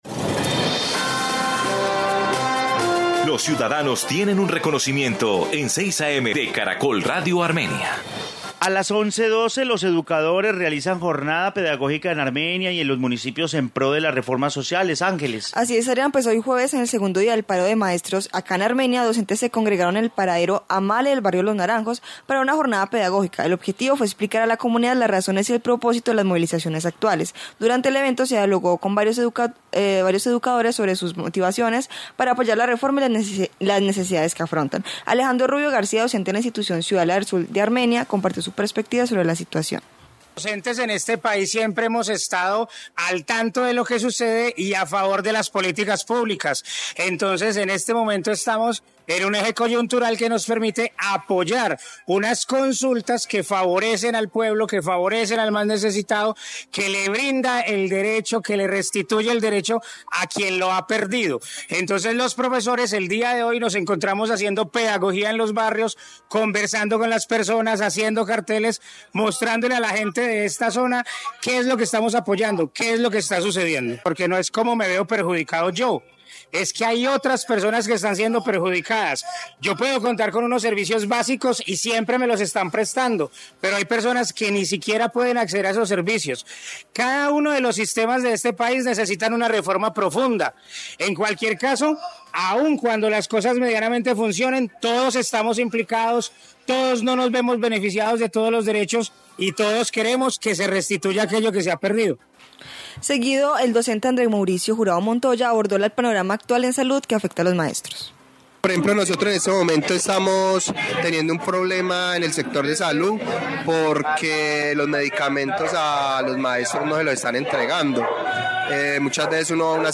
Informe segundo día de paro en Armenia